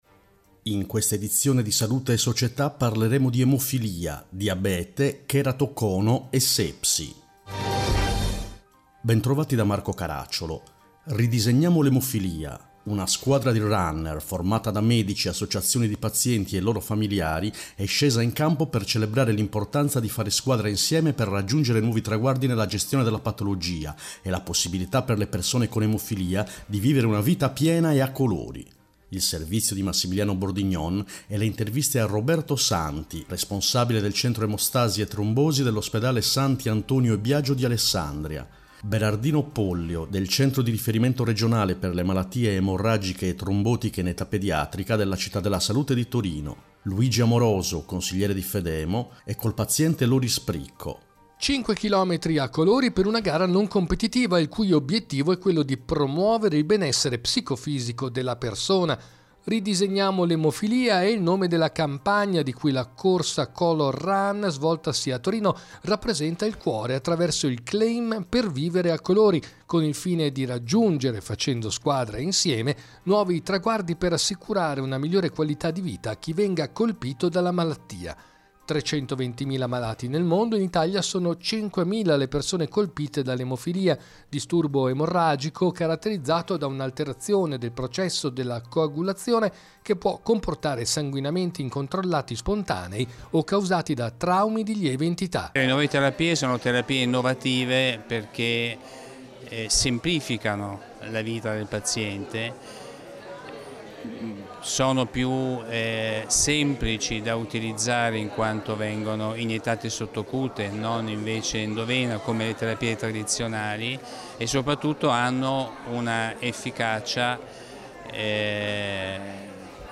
In questa edizione: 1. Emofilia, Ridisegniamo l’emofilia 2. Diabete, Città diabetogene 3. Cheratocono, Mese di prevenzione 4. Sepsi, Call to action Interviste